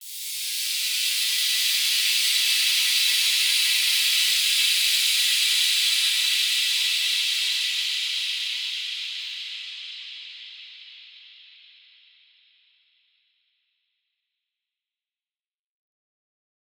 Index of /musicradar/shimmer-and-sparkle-samples/Filtered Noise Hits
SaS_NoiseFilterD-07.wav